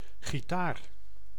Ääntäminen
IPA: [ɣi.taːɾ]